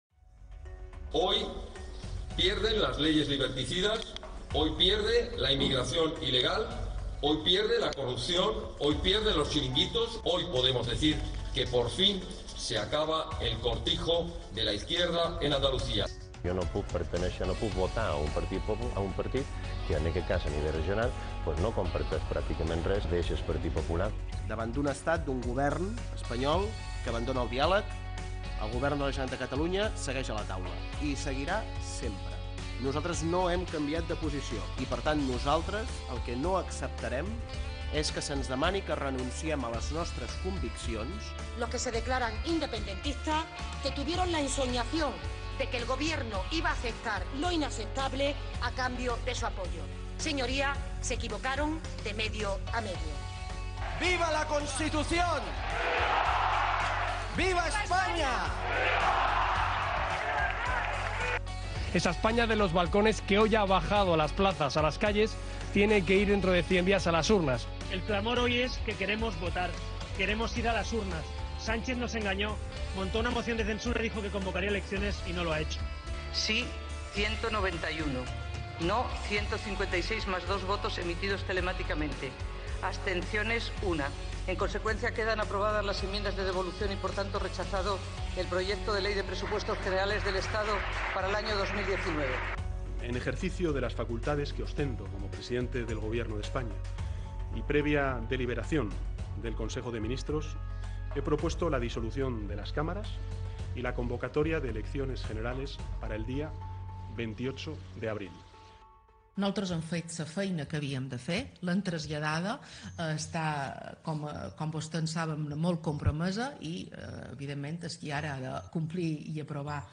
L'equip d'informatius d'IB3 Ràdio ha resumit amb 31 talls de veu els titulars que sumen vuit minuts la informació política de 2019 en l'àmbit local i l'estatal. Un període ajustat entre el 1r de gener i la data de les eleccions generals del 10N